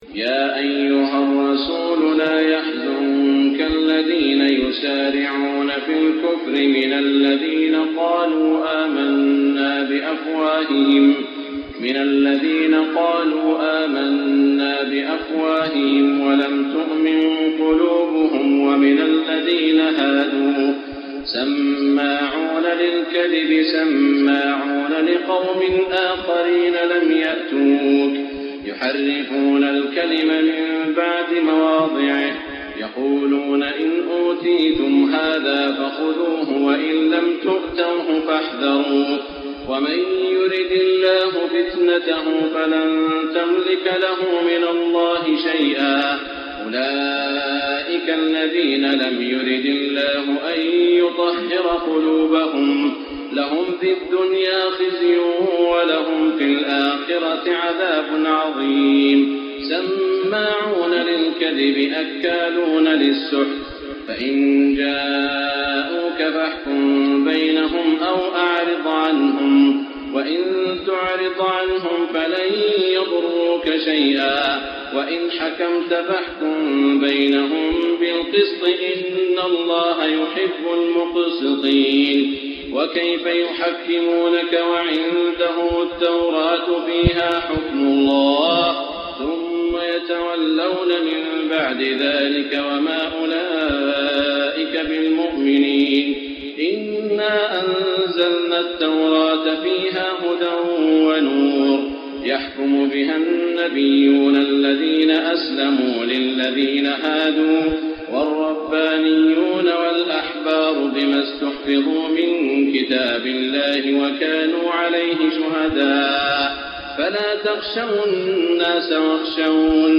تهجد ليلة 26 رمضان 1425هـ من سورة المائدة (41-81) Tahajjud 26 st night Ramadan 1425H from Surah AlMa'idah > تراويح الحرم المكي عام 1425 🕋 > التراويح - تلاوات الحرمين